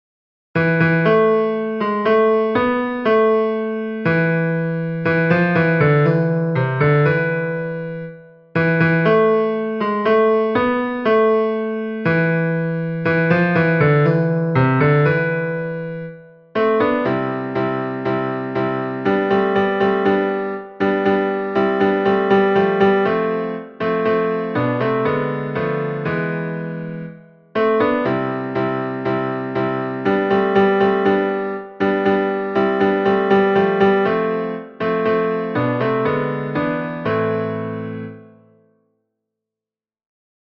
Ténors 1